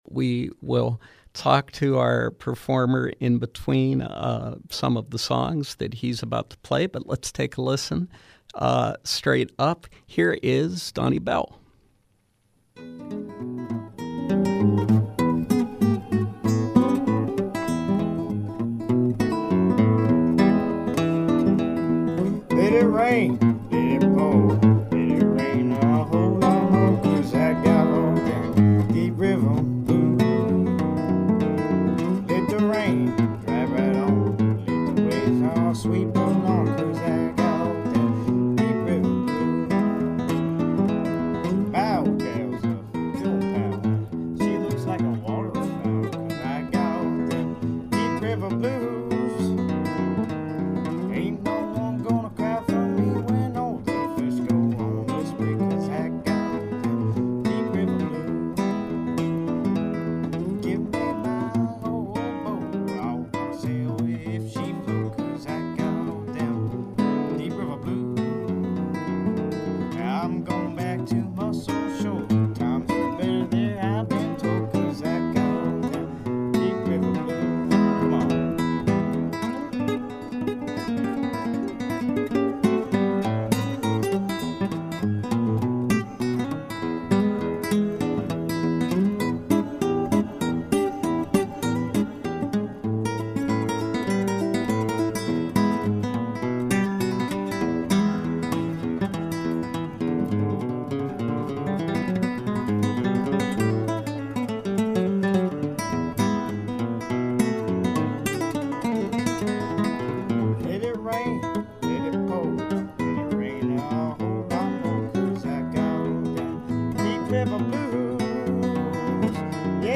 Live Performance
guitar